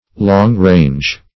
long-range - definition of long-range - synonyms, pronunciation, spelling from Free Dictionary